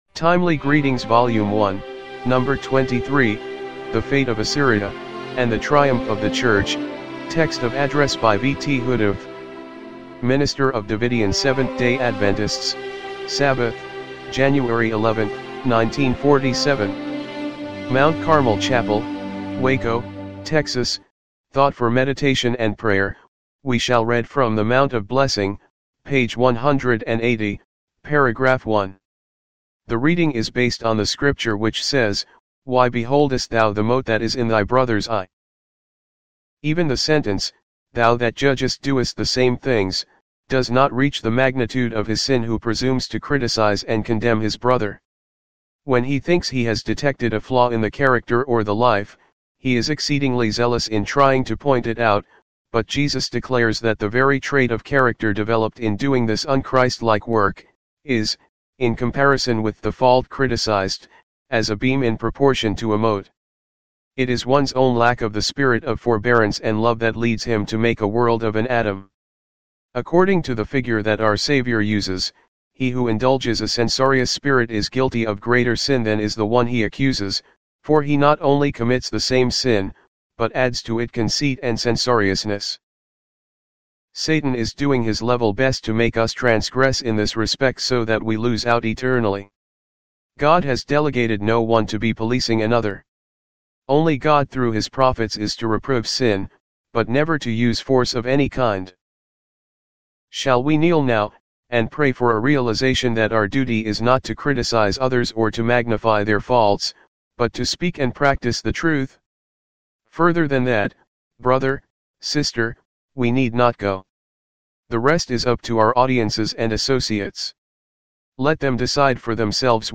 timely-greetings-volume-1-no.-23-mono-mp3.mp3